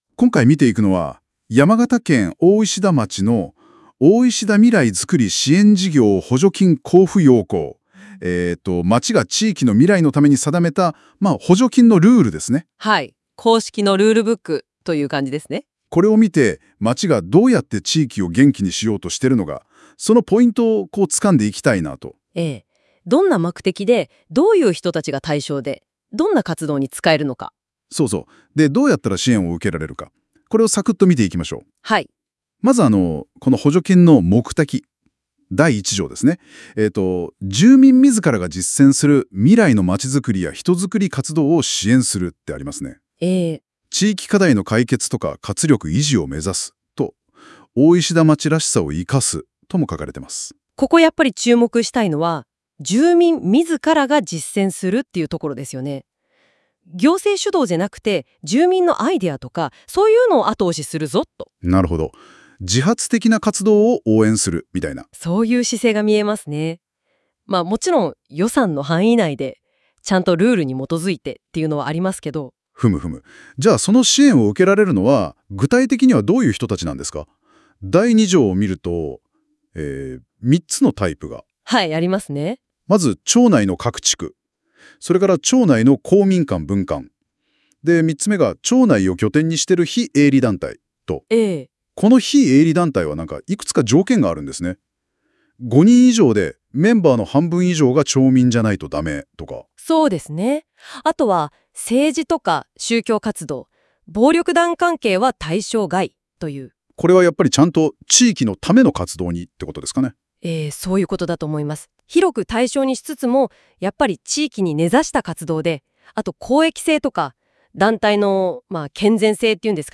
音声解説について
生成AIによる音声解説です。制度について、2人の話者による会話でわかりやすく確認できます。